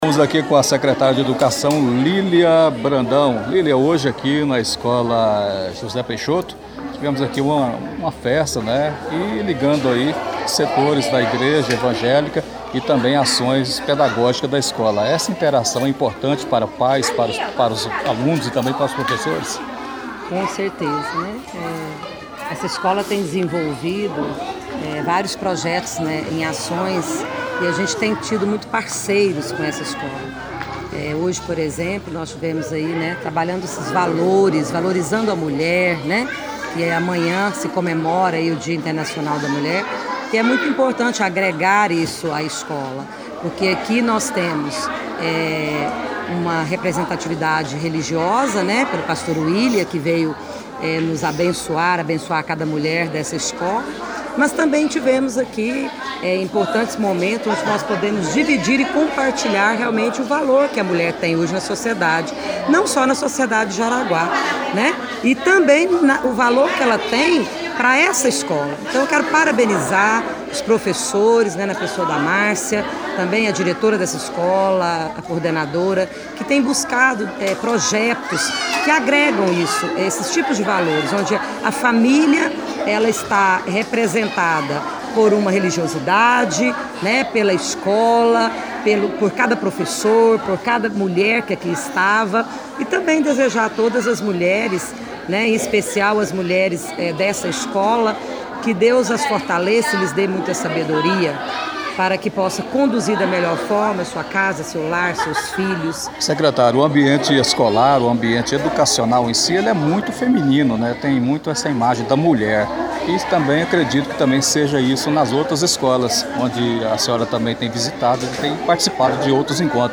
Nesta quarta-feira, (7), a escola promoveu um encontro entre pais, alunos, professores e representantes do poder público municipal, representado na ocasião pela vice-prefeita Simone Margarete e pela secretaria de educação, Lilian Brandão. (Ouça a entrevista com Lilian Brandão):
entrevista-lilian.mp3